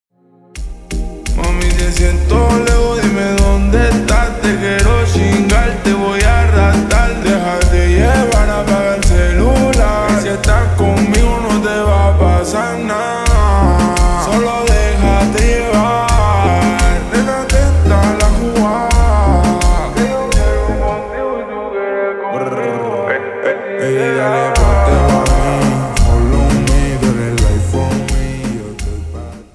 Slow Reverb Version
• Simple and Lofi sound
• High-quality audio
• Crisp and clear sound